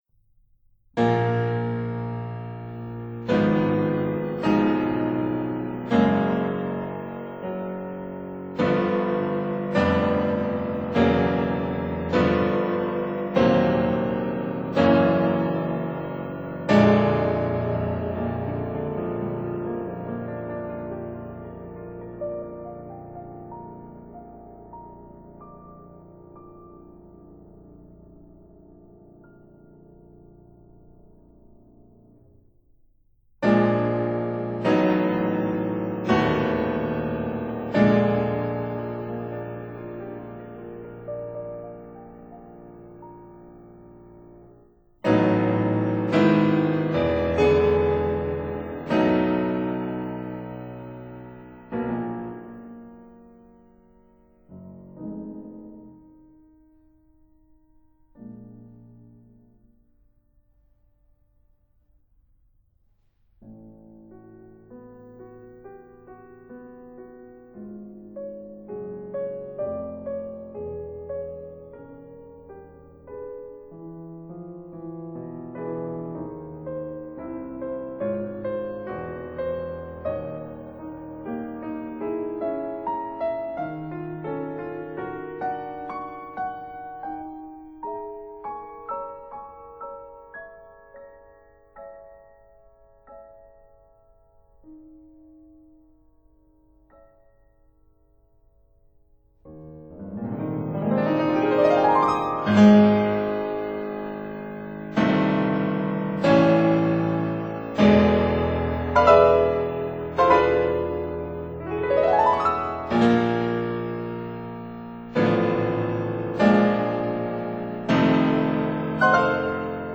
piano Date